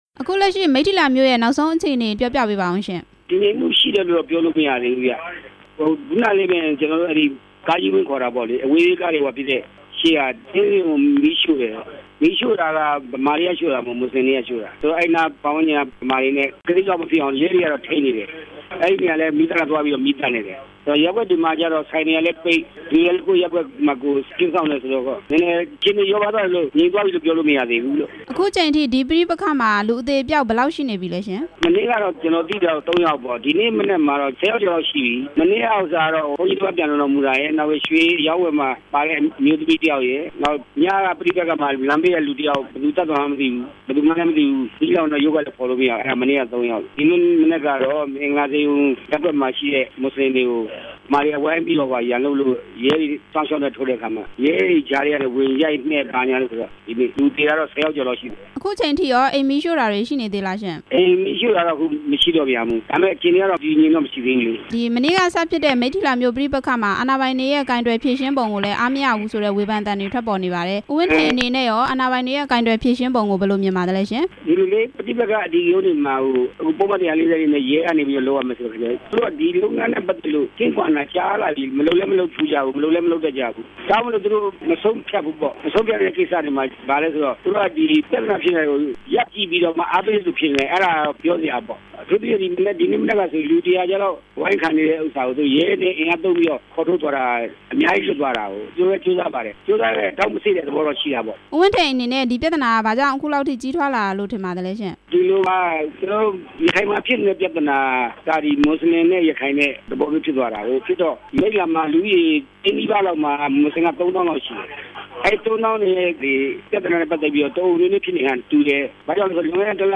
မိတ္ထီလာ ပြည်သူ့လွှတ်တော် ကိုယ်စားလှယ် ဦးဝင်းထိန်နဲ့ မေးမြန်းချက်